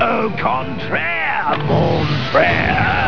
From Spider-Man: The Animated Series.